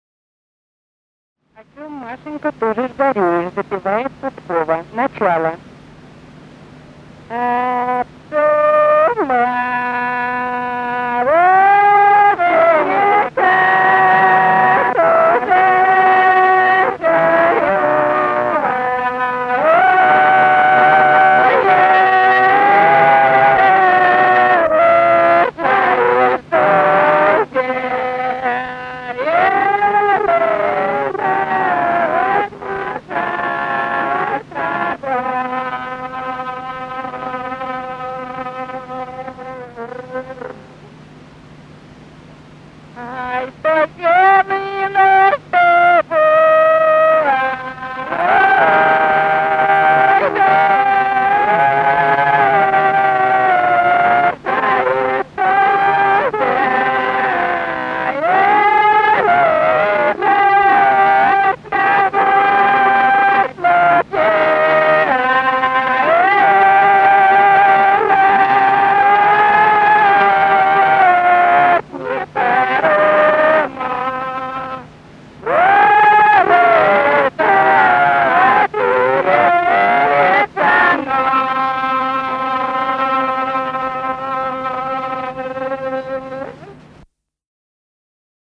Русские народные песни Владимирской области 4. Об чём, Машенька, тужишь-горюешь (лирическая) с. Дмитриевы Горы Ляховского (с 1963 Меленковского) района Владимирской области.